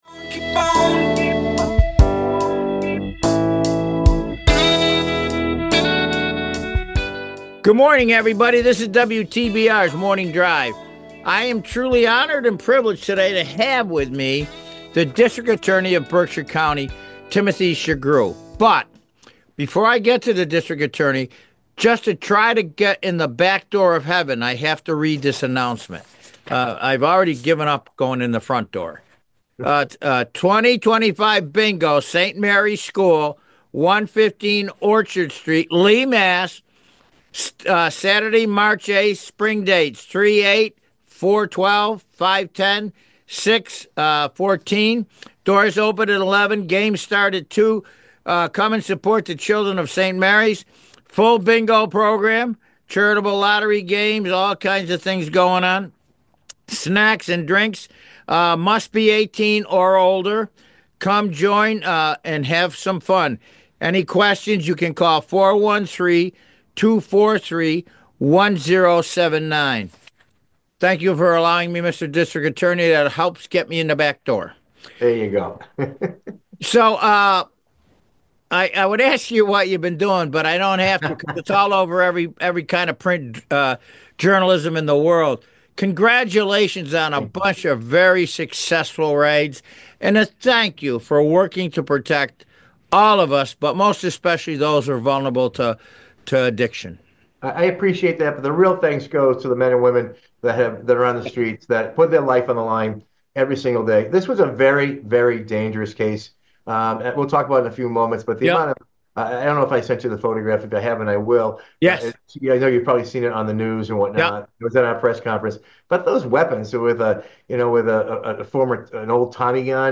talking with Berkshire District Attorney Timothy Shugrue